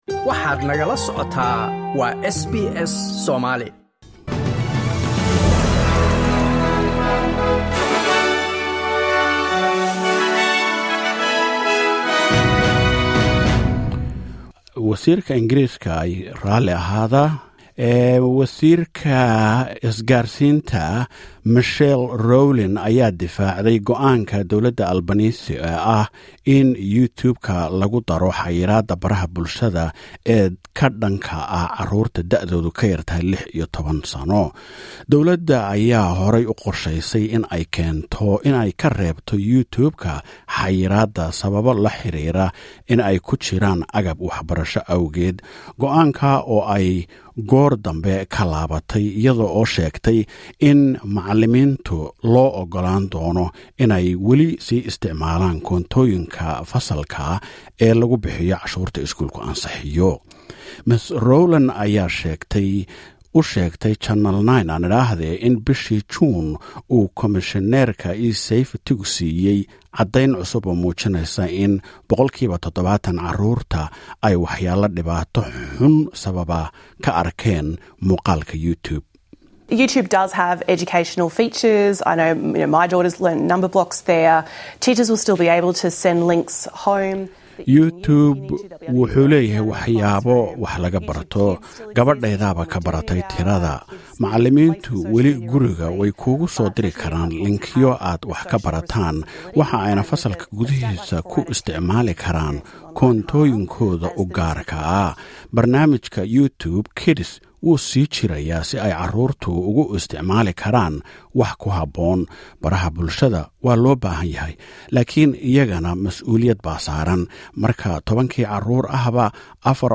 SBS News Somali: 30 July 2025